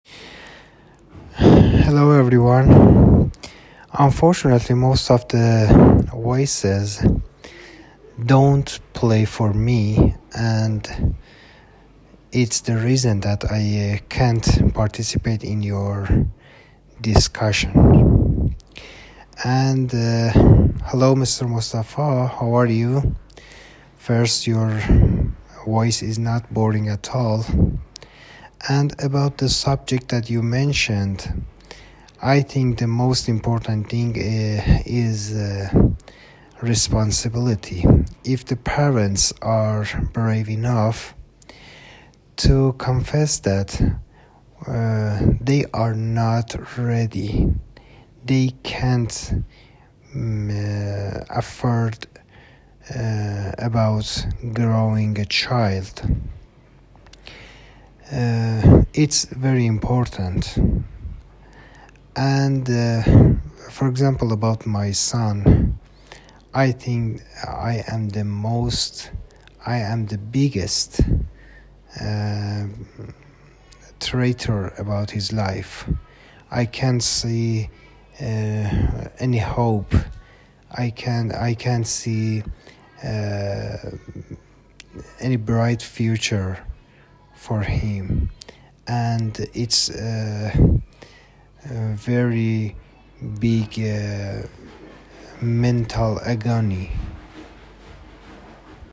What interesting stereophonic music.